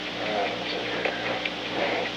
On October 26, 1971, President Richard M. Nixon and unknown person(s) met in the President's office in the Old Executive Office Building at an unknown time between 1:51 pm and 2:49 pm. The Old Executive Office Building taping system captured this recording, which is known as Conversation 303-007 of the White House Tapes.